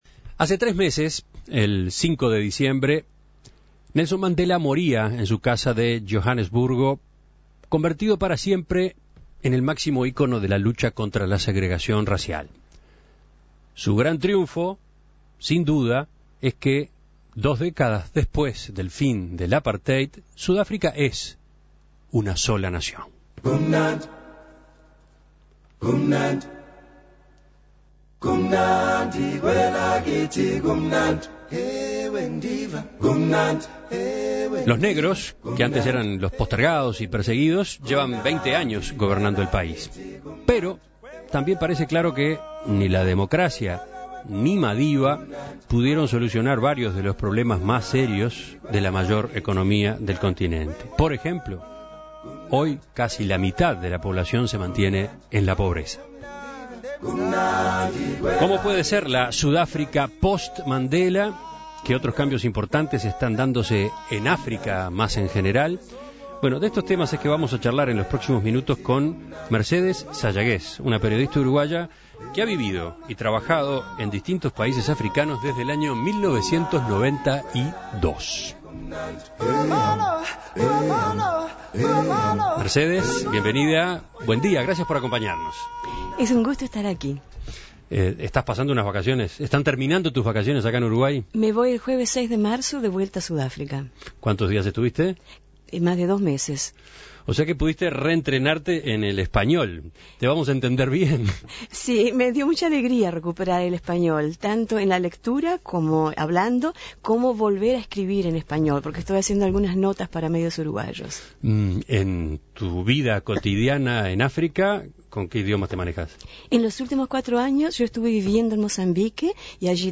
Hace tres meses, el 5 de diciembre, Nelson Mandela murió en su casa de Johanesburgo, convertido en el máximo ícono de la lucha contra la segregación racial. En diálogo con En Perspectiva